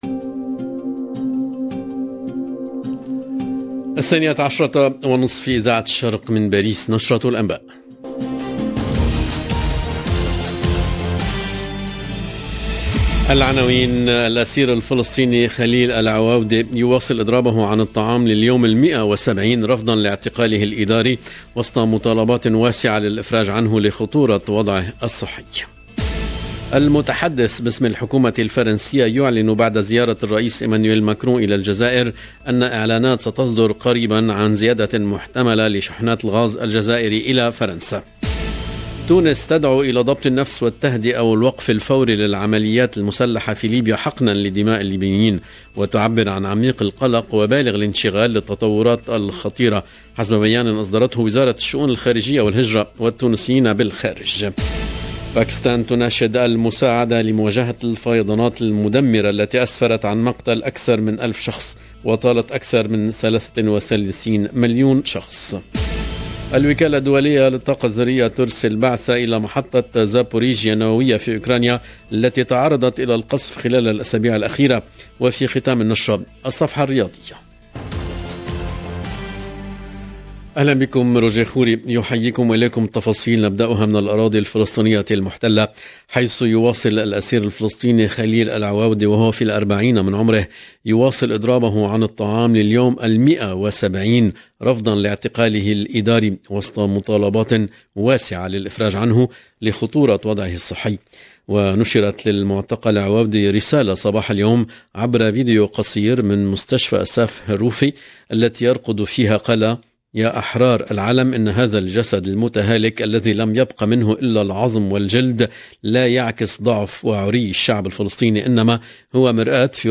LE JOURNAL EN LANGUE ARABE DE MIDI 30 DU 29/08/22